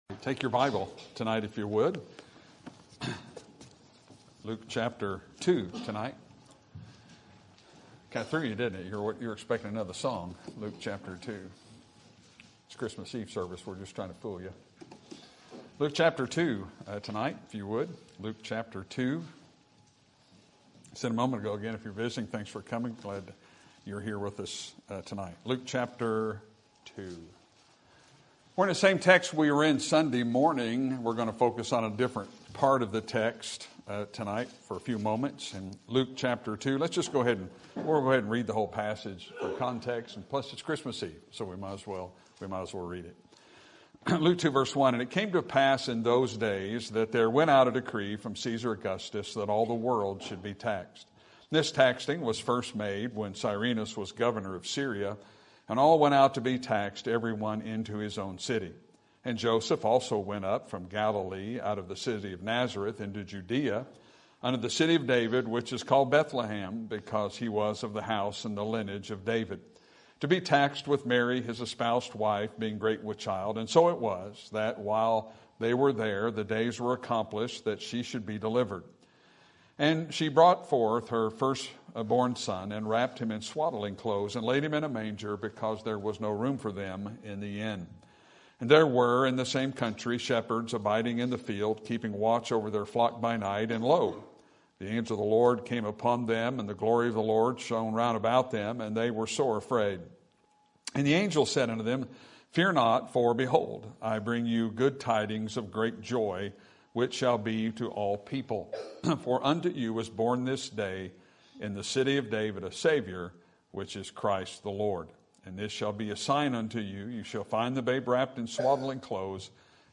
Sermon Topic: General Sermon Type: Service Sermon Audio: Sermon download: Download (20.1 MB) Sermon Tags: Luke Name Angel Jesus